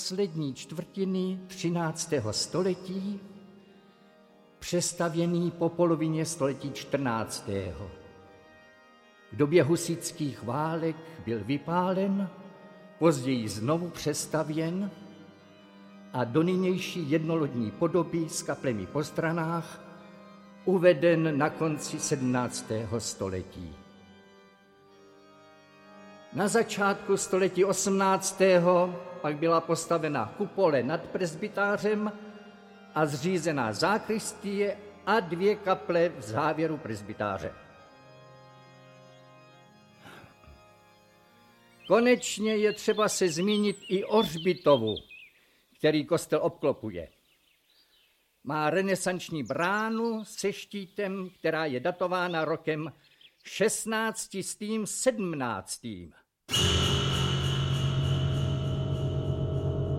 Audiobook
Audiobooks » Short Stories
Read: Petr Čepek